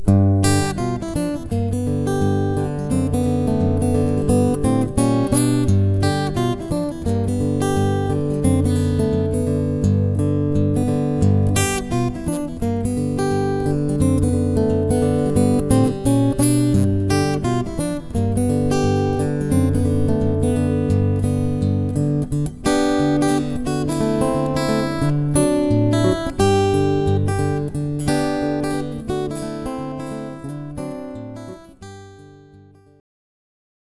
Played on my trusty old Takamine.